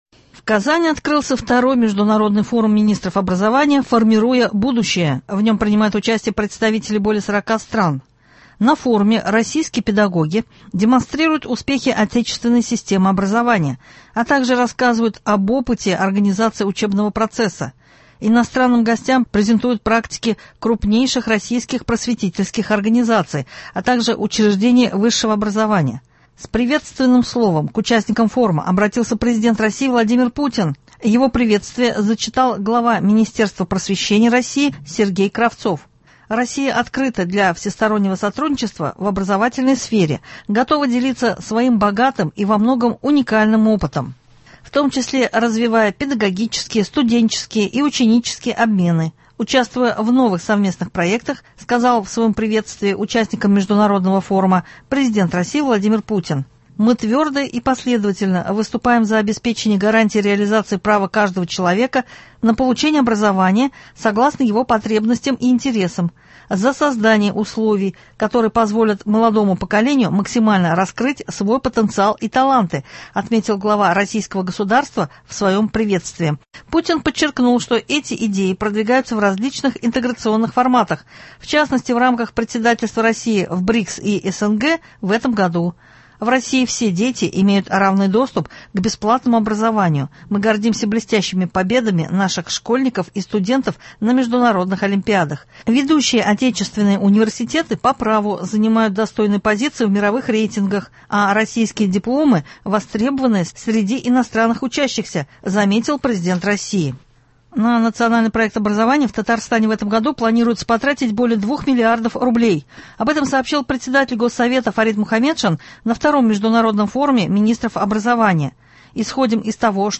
Приветствие главы государства зачитал глава министерства просвещения РФ Сергей Кравцов.